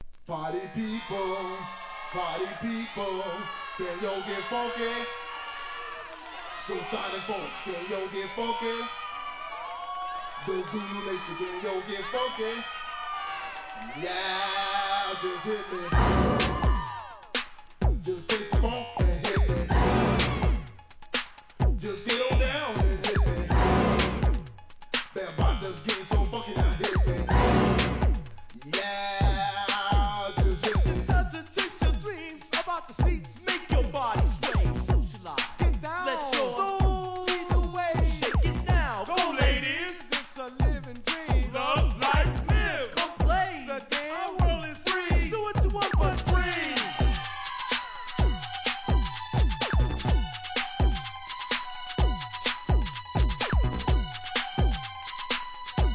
エレクトロCLASSIC集